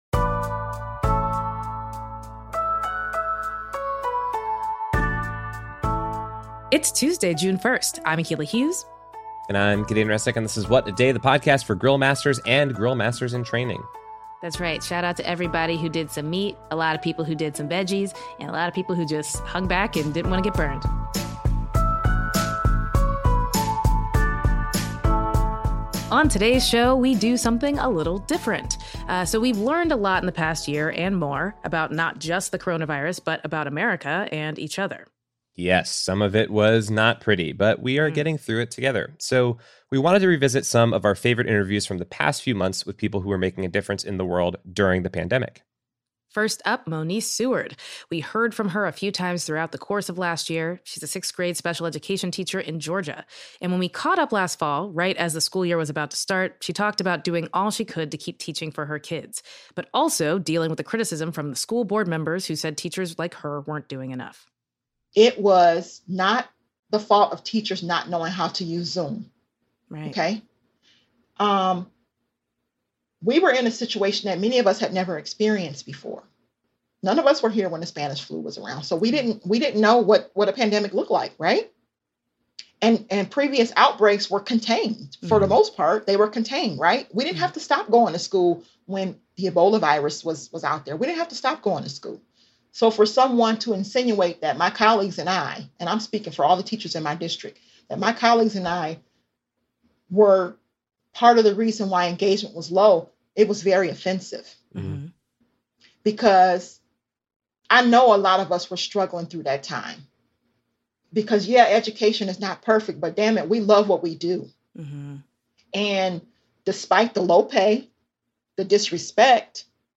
Revisiting Our Favorite Conversations on Coronavirus